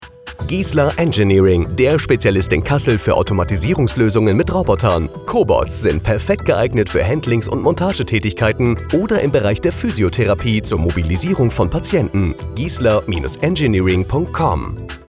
Giesler Engineering Radiospot
Funkspot_Giesler+Engineering+GmbH+(15+Sek).mp3